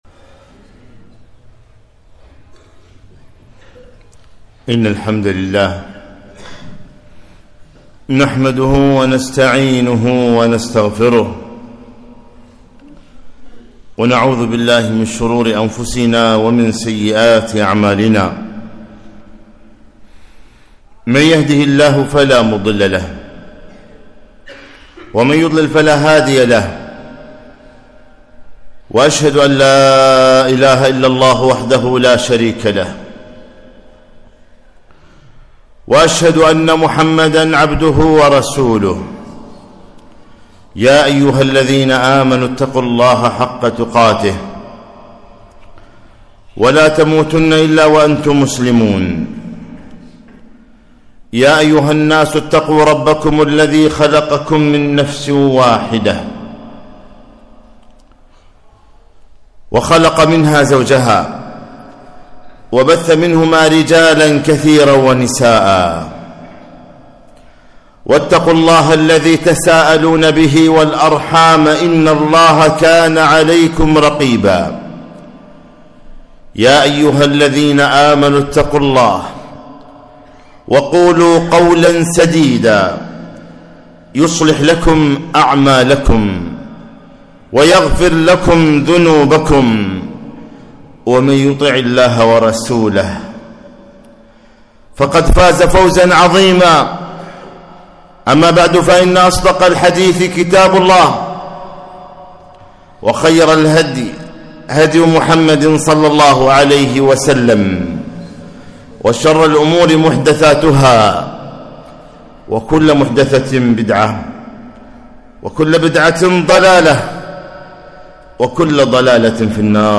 خطبة - القرب من الله تعالى